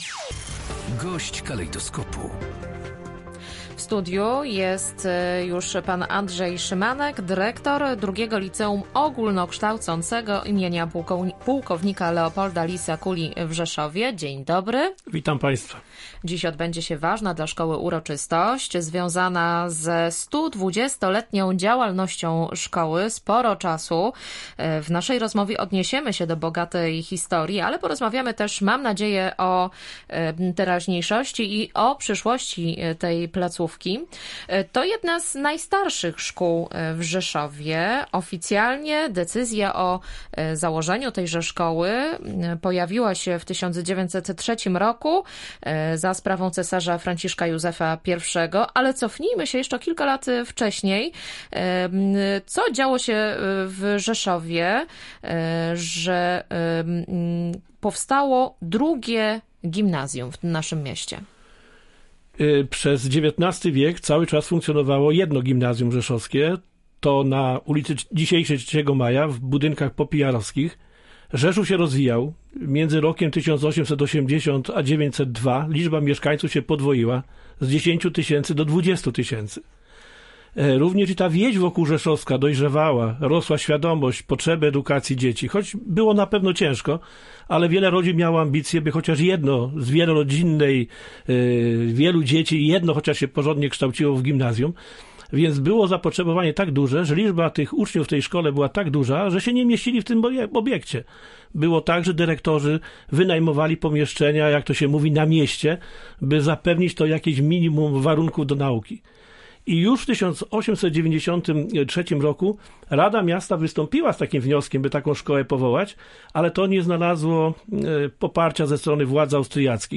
Gość dnia • II Liceum Ogólnokształcące im. płk.